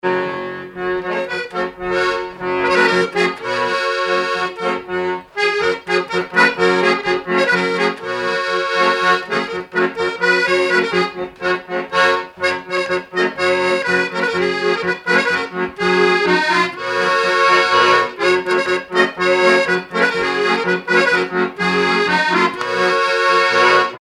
Magland
circonstance : bal, dancerie
Pièce musicale inédite